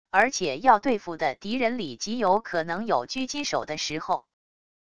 而且要对付的敌人里极有可能有狙击手的时候wav音频生成系统WAV Audio Player